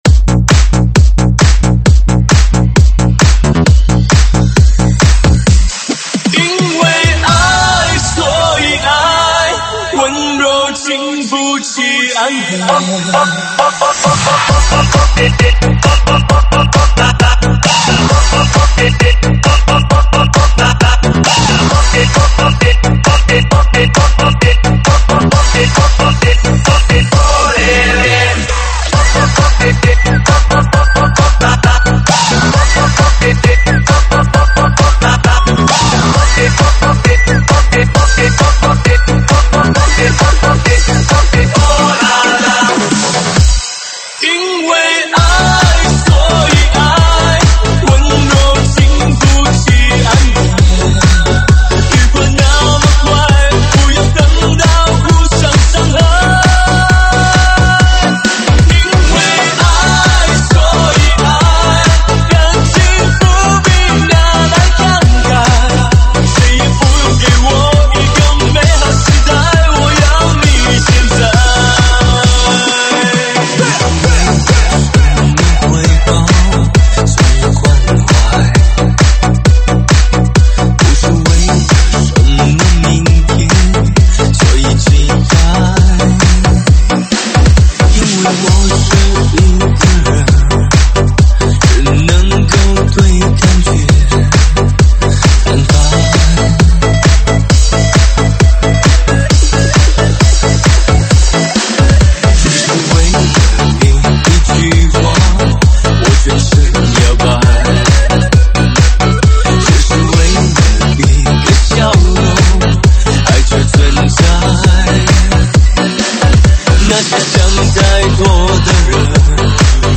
中文慢摇